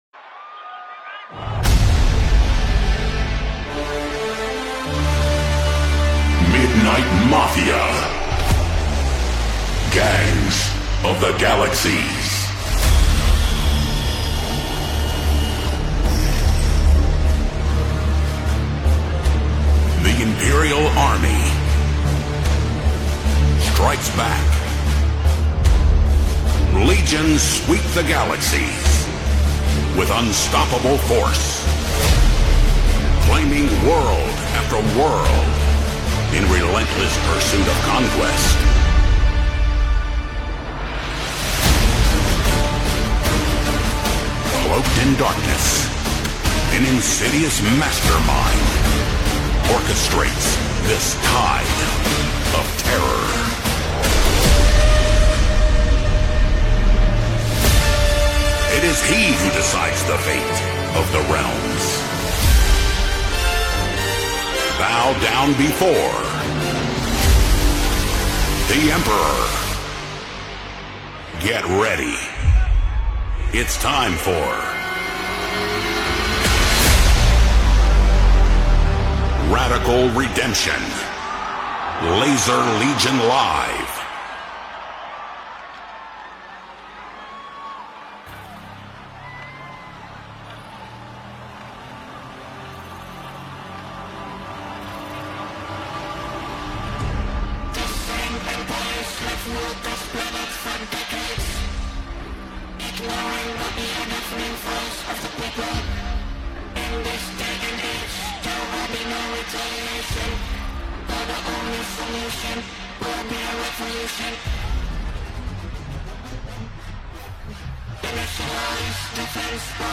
liveset